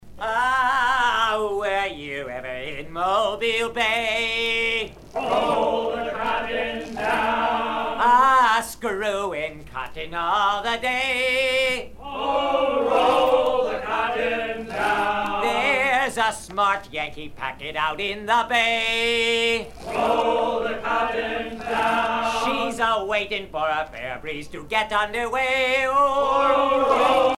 Fonction d'après l'analyste gestuel : à hisser à grands coups
Usage d'après l'analyste circonstance : maritimes
Sea chanteys and sailor songs